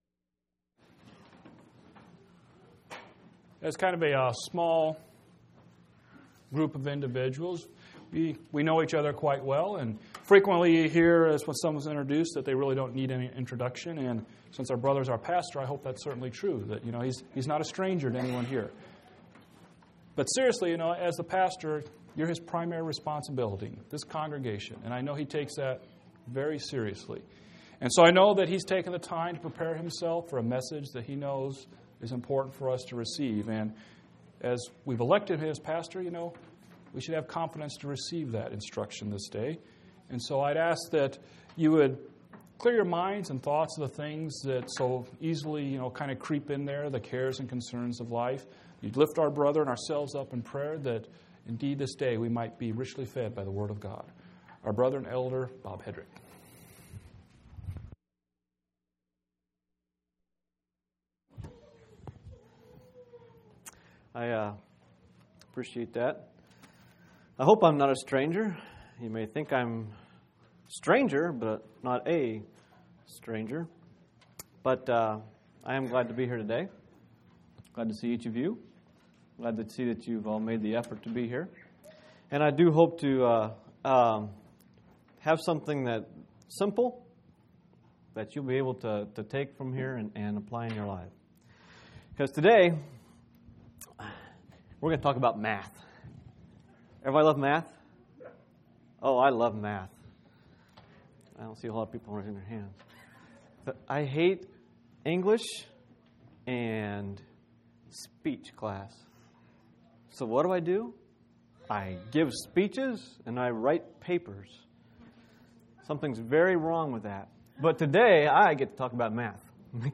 8/28/2005 Location: Phoenix Local Event